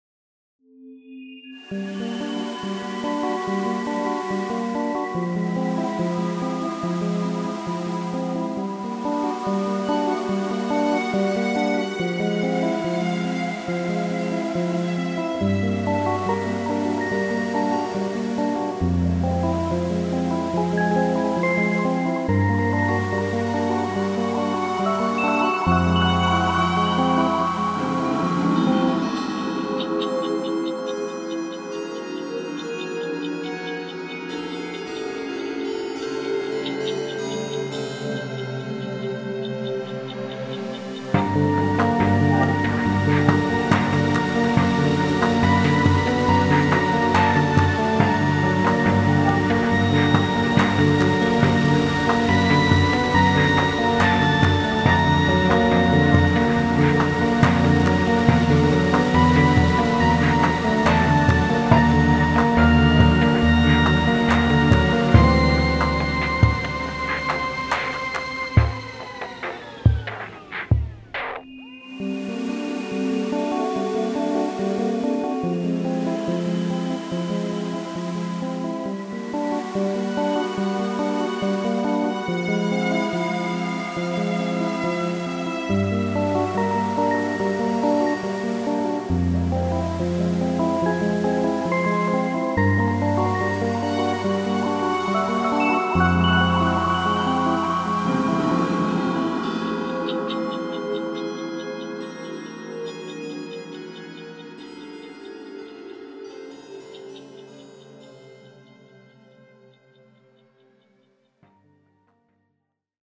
ループ可
アンビエント
シンセ
エレピ
暗い
不安
ミステリアス